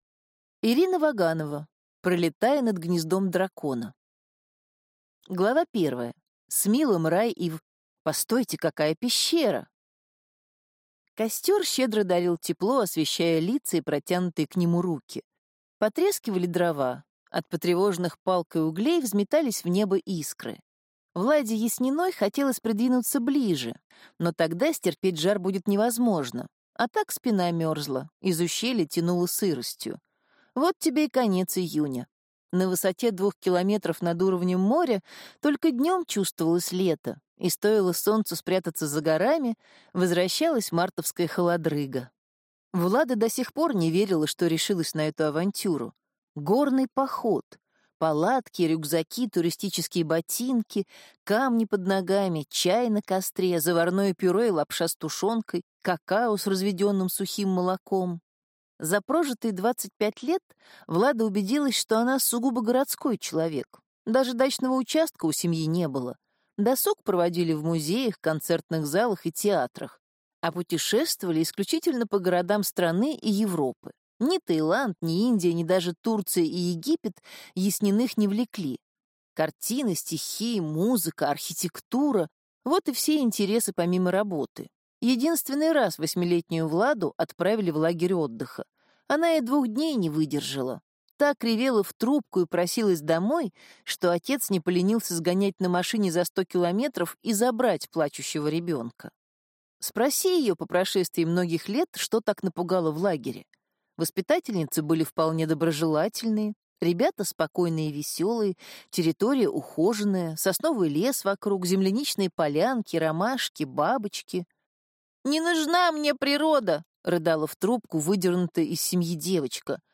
Аудиокнига Пролетая над гнездом дракона | Библиотека аудиокниг
Прослушать и бесплатно скачать фрагмент аудиокниги